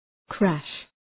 Προφορά
{kræʃ}